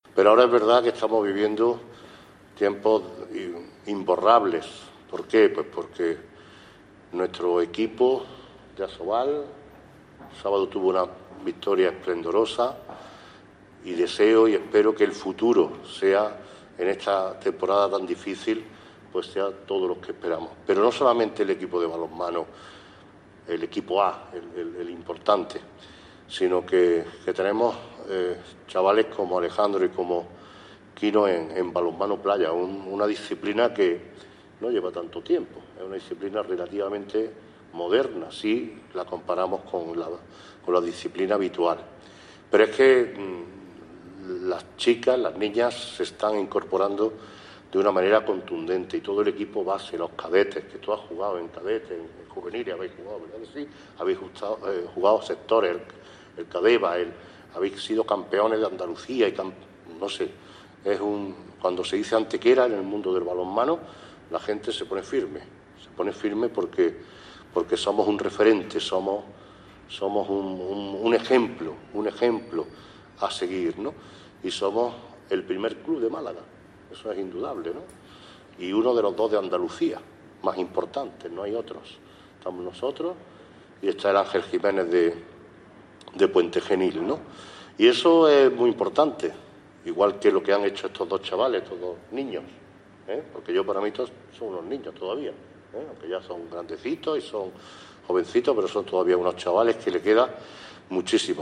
El alcalde de Antequera, Manolo Barón, y el teniente de alcalde delegado de Deportes, Juan Rosas, han presidido en la tarde de este martes una recepción en el Salón de Plenos a jugadores y entrenadores de balonmano en nuestra ciudad que han cosechado recientemente éxitos destacados en competiciones nacionales e internacionales.
Cortes de voz